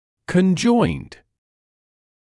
[kən’ʤɔɪnd][кэн’джойнд]соединённый, объединённый; 2-я и 3-я форма от to conjoin